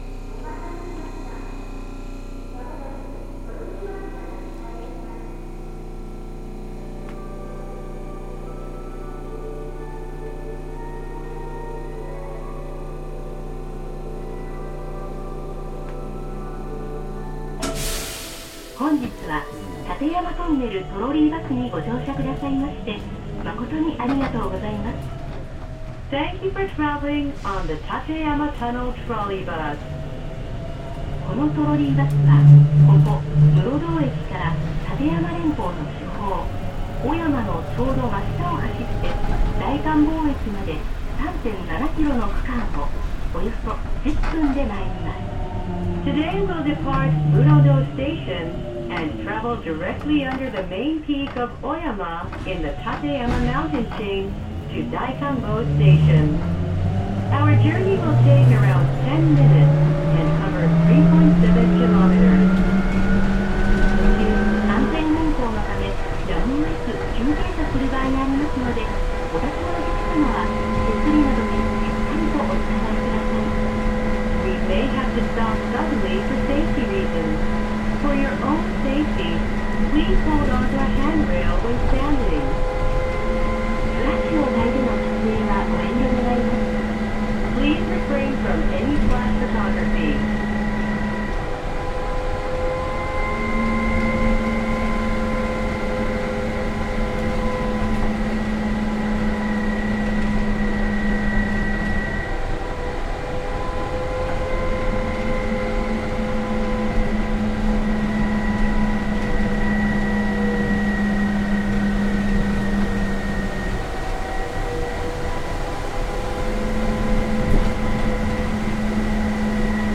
走行音(中扉付近から後ろ向き)[tkk8000b.mp3/MP3-128kbps Stereo/7.50MB]
区間：室堂→信号場→大観峰 New!
※走行音録音のためのイベントでの録音です。2ファイルではマイクの場所や向きが違います。
種類：VVVFインバータ制御(東芝GTO第2世代、1C1M、発電ブレーキ)